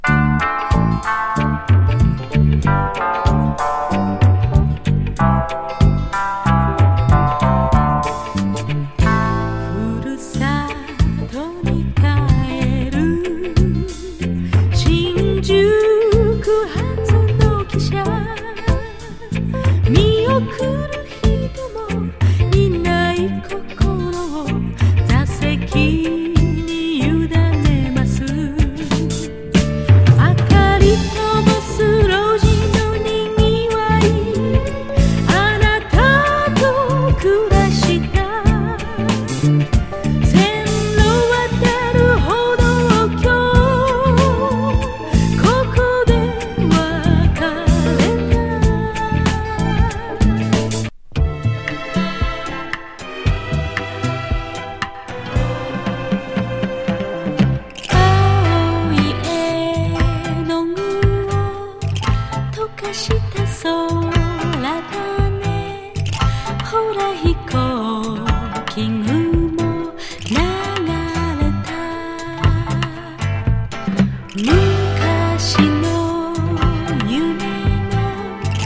妙アレンジ・フォーキー・レゲー